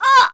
Seria-Vox_Damage_01_kr.wav